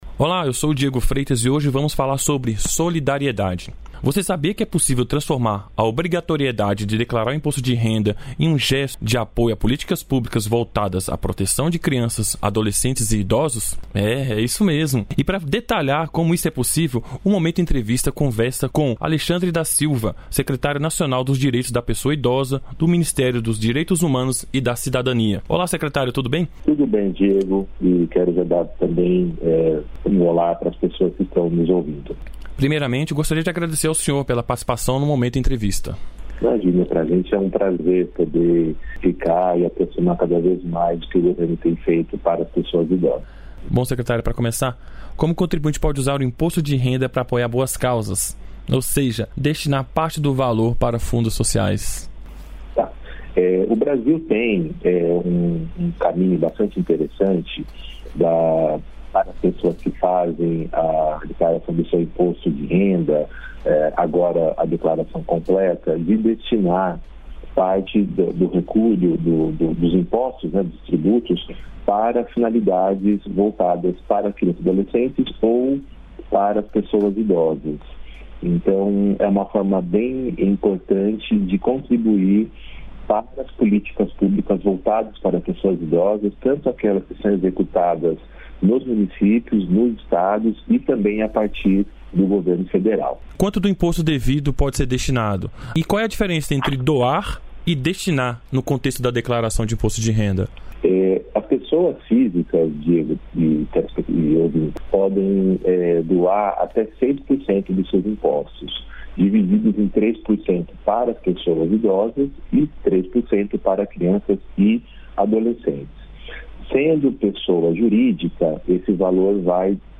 Quem fala sobre o assunto é a secretária Nacional de Mudança do Clima, Ana Toni, e o secretário Nacional de Participação Social, Renato Simões.